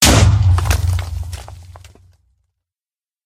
explode3.mp3